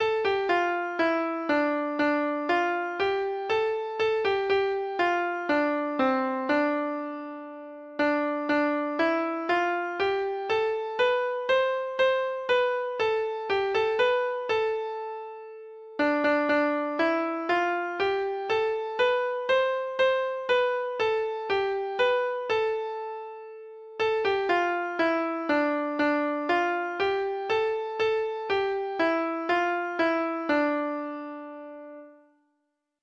Free Sheet music for Treble Clef Instrument
Traditional Music of unknown author.